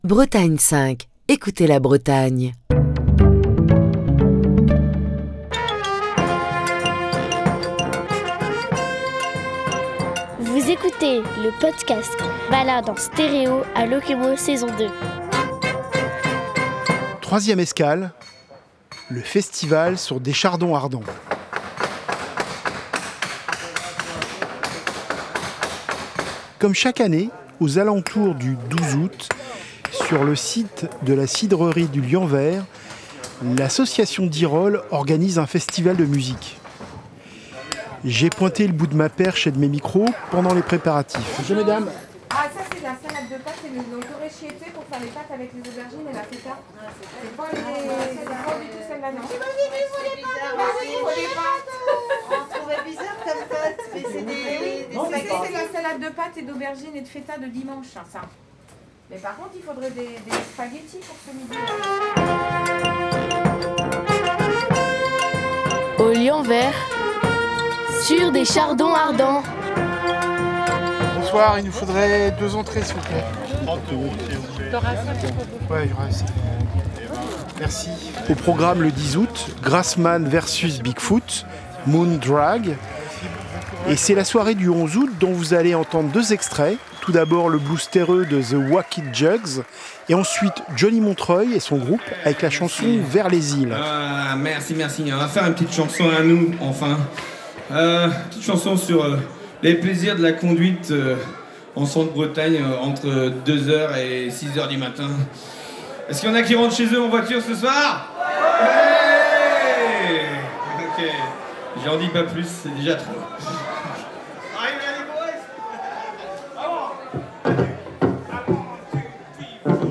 Ce neuvième épisode de Balade en stéréo à Lokémo nous conduit au Festival sur des Chardons Ardents, pour écouter de la musique des marais et du rock'n'roll de Montreuil.